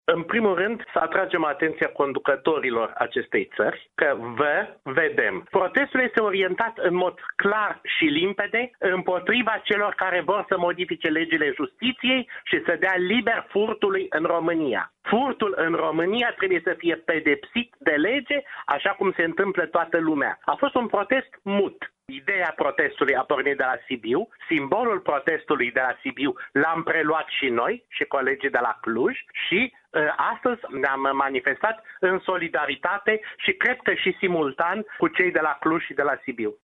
unul dintre protestatari